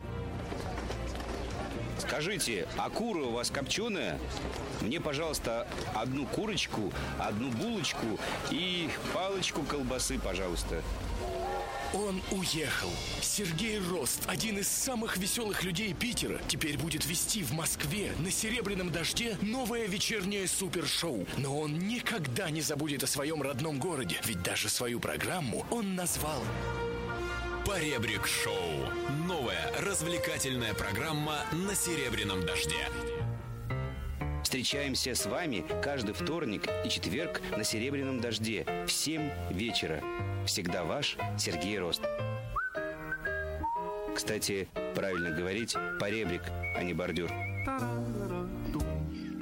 Анонс программы "Поребрик шоу" (Серебряный дождь, 07.02.2006)